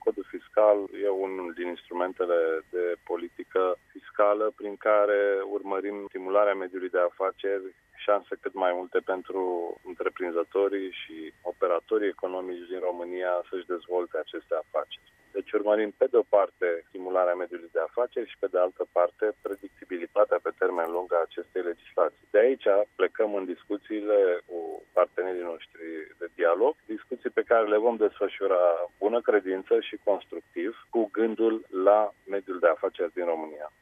Prim-vicepreşedintele PNL, Cătălin Predoiu, a precizat, astăzi, că sprijinirea eficientă a mediului de afaceri este principiul pe care liberalii îl au în vedere în cadrul oricăror negocieri privind Codul Fiscal: